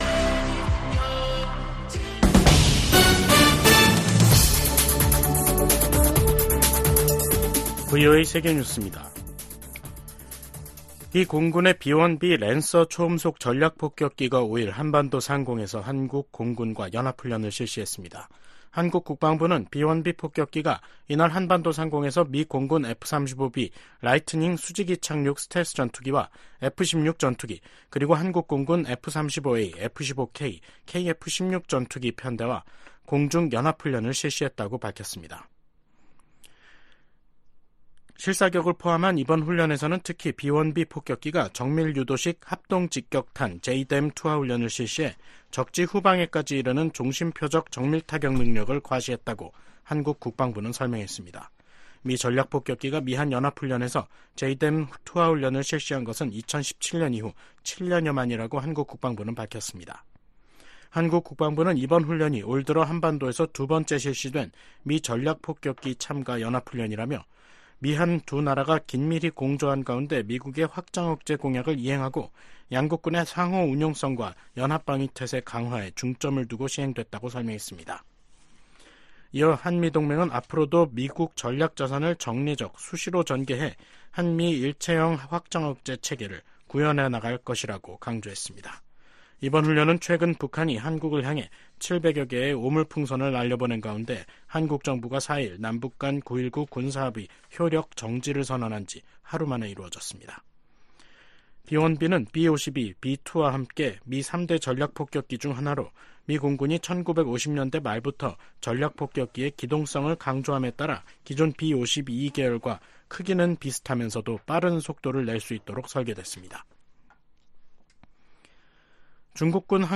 VOA 한국어 간판 뉴스 프로그램 '뉴스 투데이', 2024년 6월 5일 3부 방송입니다. 미국, 한국, 일본이 국제원자력기구 IAEA 정기 이사회에서 북한과 러시아의 군사 협력 확대를 비판하며 즉각 중단할 것을 한목소리로 촉구했습니다. 백악관이 북한 정권의 대남 오물풍선 살포 등 도발과 관련해 큰 우려를 가지고 주시하고 있다며 평양이 불필요한 행동을 계속하고 있다고 비판했습니다.